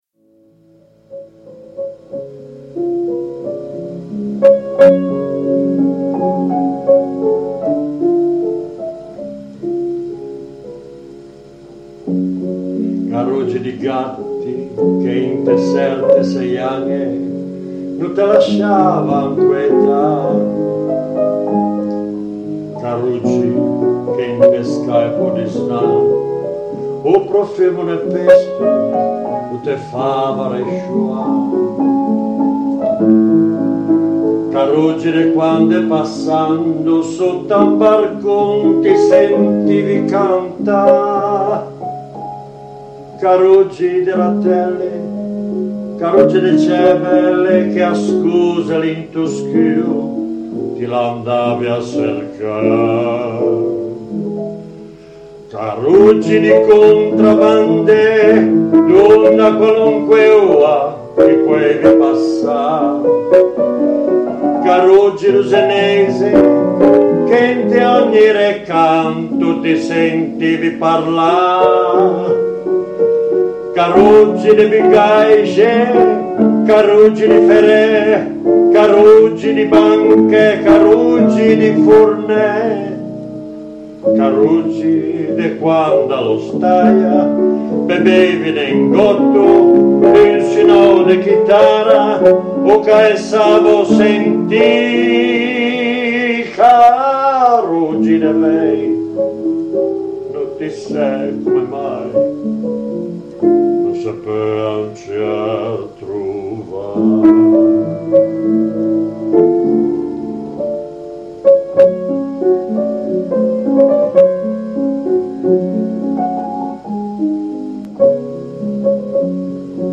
[ Cansoìn zenéixi ]